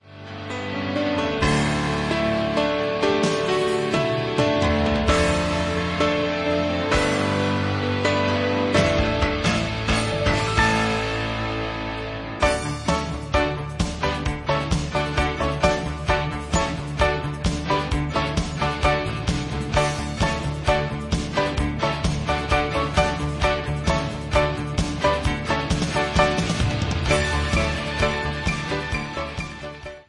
The live piano accompaniment that plays with the album